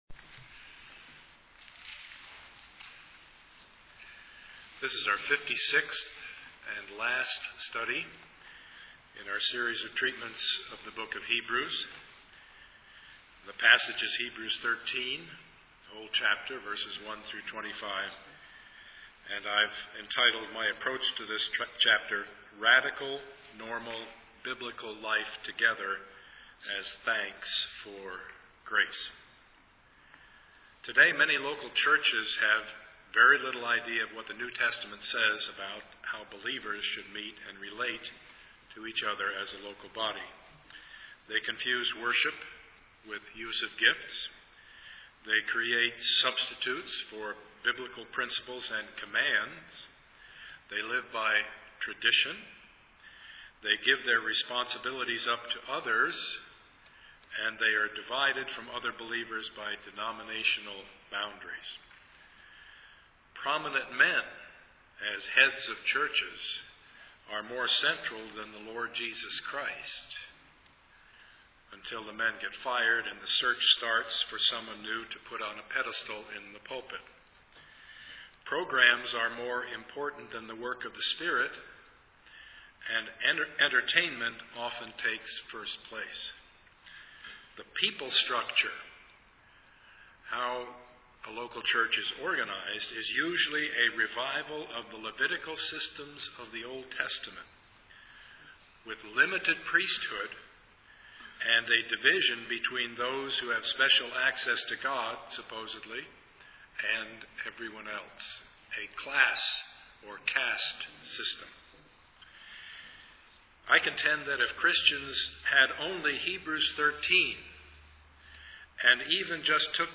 Service Type: Sunday morning
Part 56 of the Sermon Series